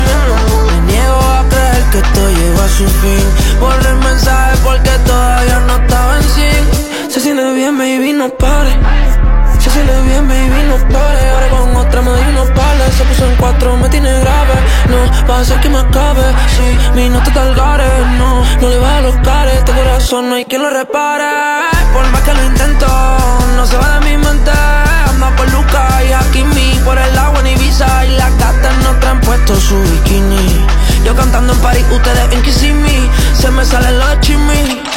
Жанр: Латиноамериканская музыка
# Urbano latino